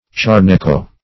Search Result for " charneco" : The Collaborative International Dictionary of English v.0.48: Charneco \Char"ne*co\, Charnico \Char"ni*co\, n. A sort of sweet wine.